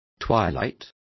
Complete with pronunciation of the translation of twilight.